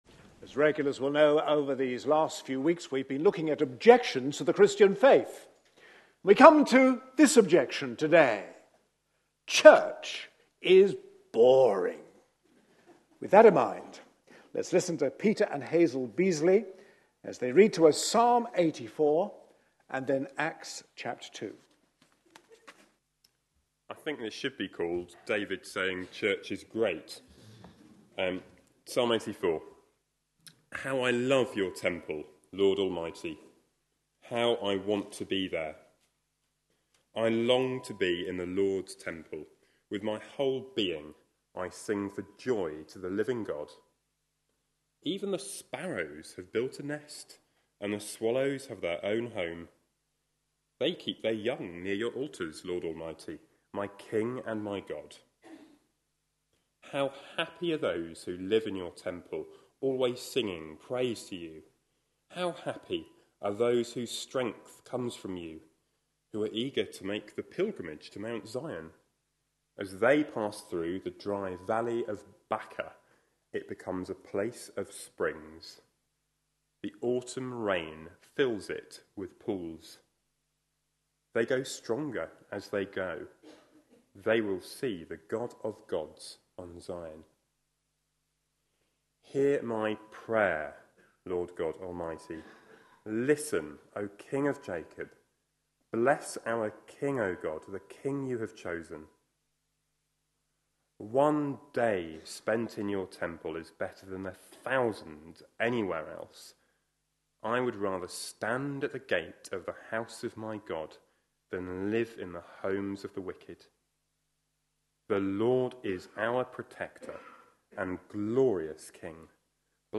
A sermon preached on 24th November, 2013, as part of our Objections to faith answered! series.